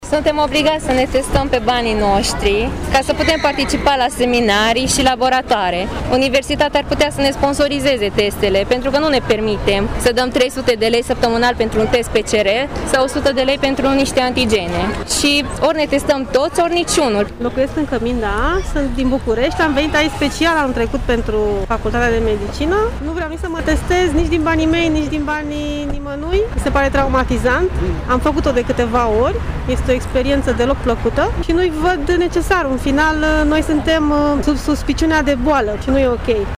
Acest fapt a determinat o parte dintre studenți să protesteze deoarece se simt discriminați, spun că nu își pot permite testări frecvente, și cer universității să găsească soluții: